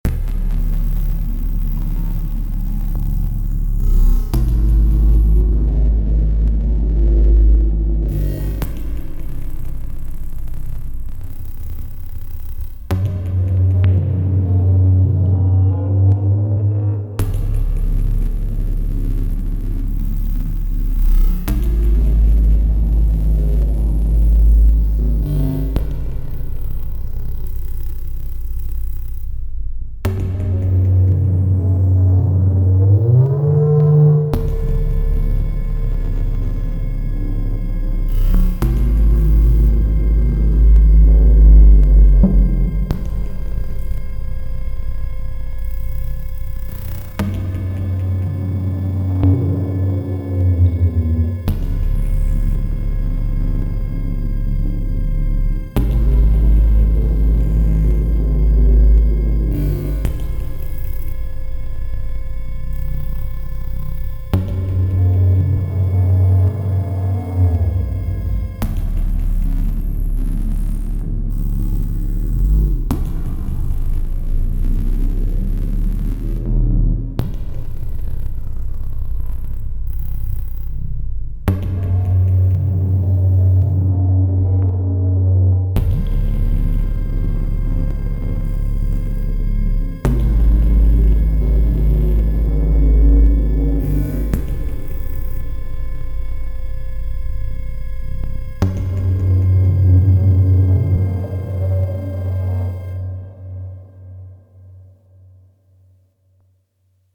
2x TOY doing evil soundscape stuff.